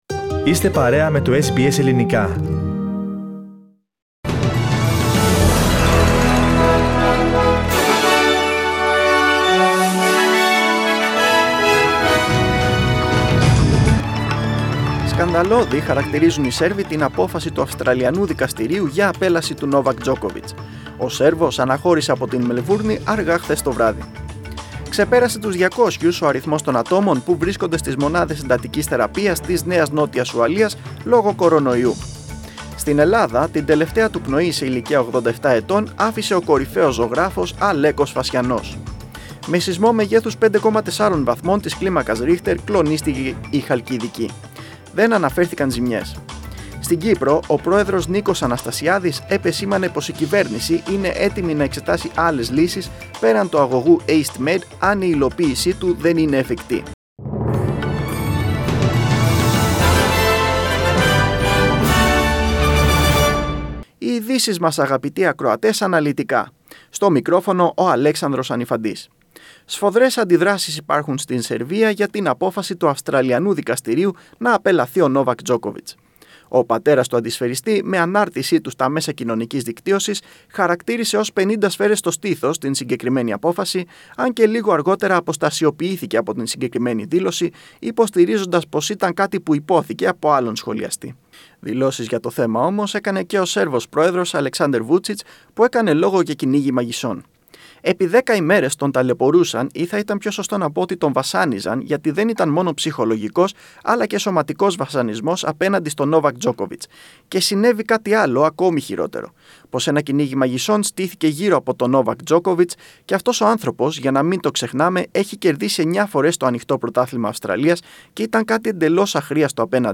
Δελτίο Ειδήσεων 17.1.22
News in Greek. Source: SBS Radio